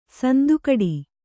♪ sandu kaḍi